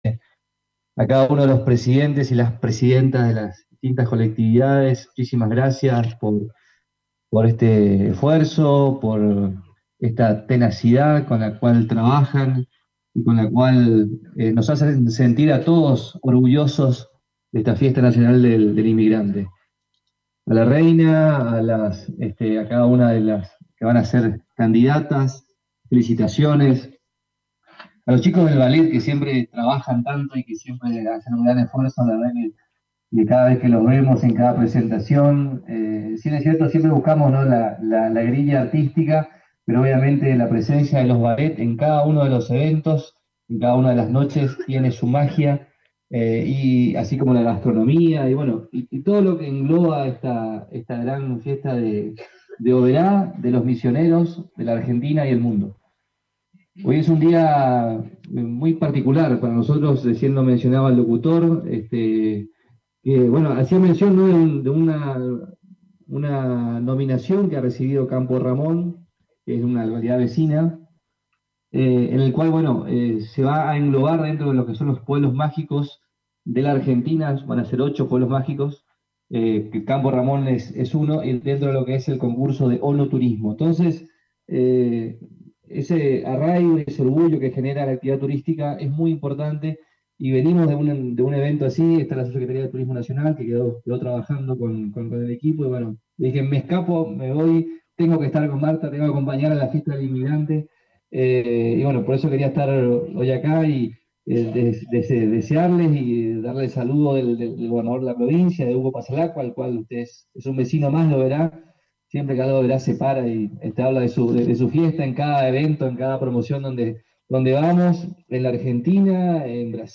En representación del gobierno de la provincia de Misiones, el ministro de turismo, José María Arrúa, participó de el acto de lanzamiento de la cuadragésimo cuarta edición de la Fiesta Nacional del Inmigrante que se realizó en el patio de la casa de la colectividad brasileña en el Parque de las Naciones de la ciudad […]